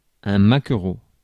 Ääntäminen
IPA: /ma.kʁo/